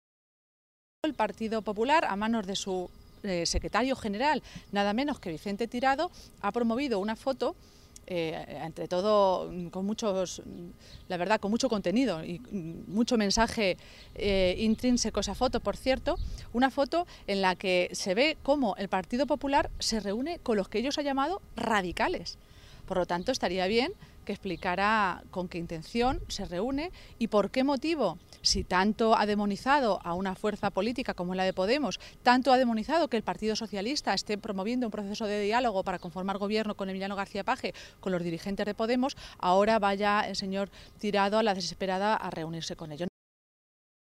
Se pronunciaba Maestre de esta manera esta mañana, en una comparecencia ante los medios de comunicación, en Toledo, durante la reunión de Page con los otros 14 diputados regionales socialistas electos en las elecciones del pasado 24 de Mayo.